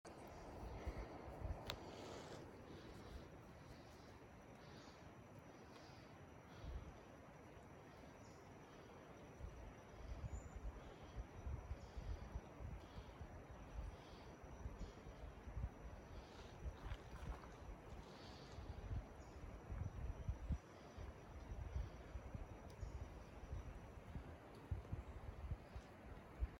More not-highway sounds.
LaFortunaForestSounts05.mp3